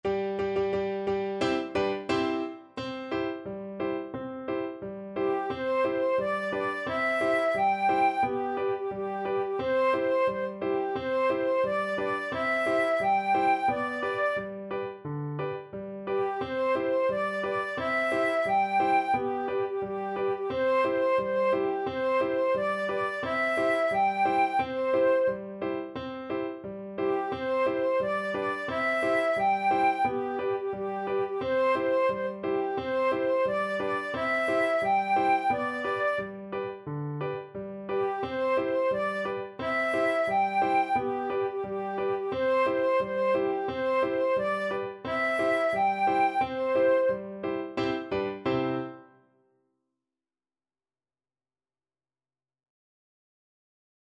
Flute
Traditional Music of unknown author.
2/4 (View more 2/4 Music)
C major (Sounding Pitch) (View more C major Music for Flute )
Steady march =c.88